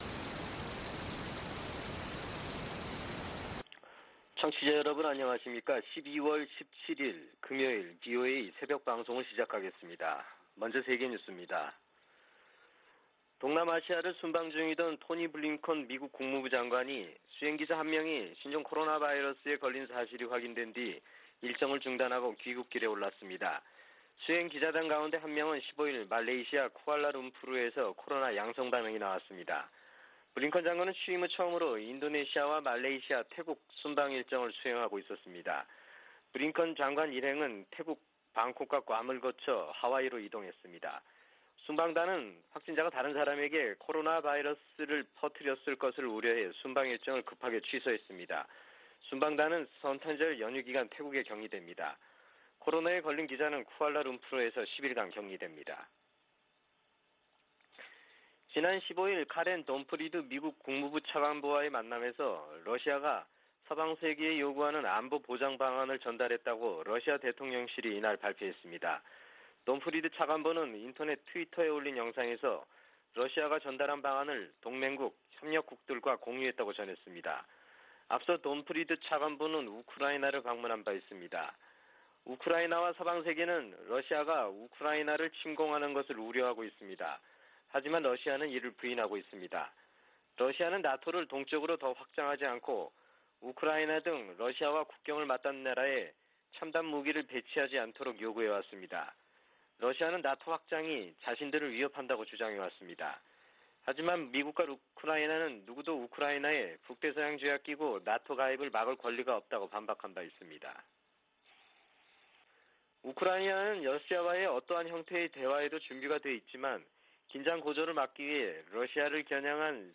VOA 한국어 '출발 뉴스 쇼', 2021년 12월 17일 방송입니다. 유엔 안보리가 올해도 북한 인권 관련 비공개 회의를 개최한 가운데, 일부 이사국들이 북한 정권의 인권유린 실태를 비판했습니다. 미 상원이 국방수권법안을 가결함에 조 바이든 대통령 서명만 거치면 효력을 갖습니다. 신종 코로나바이러스 감염증 사태가 북한에 두고 온 가족들에게 생활비를 보내 온 한국 내 탈북민들의 부담을 높이고 있습니다.